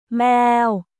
メーオ